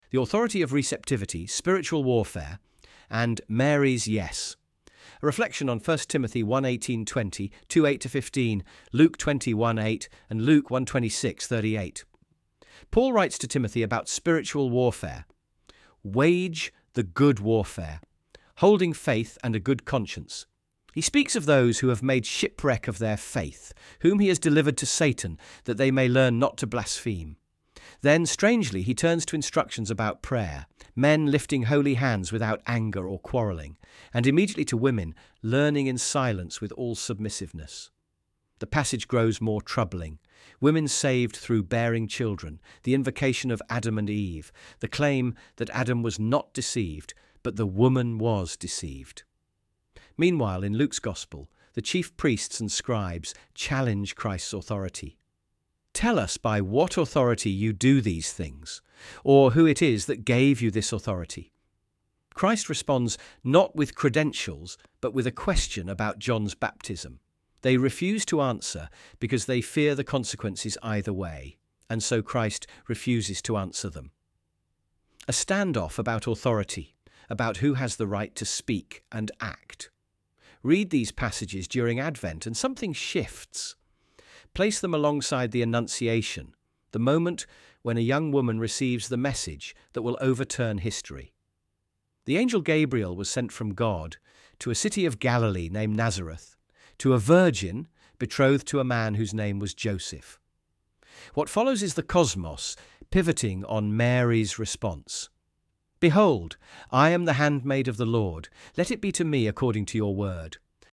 audio.texttospeech-14.mp3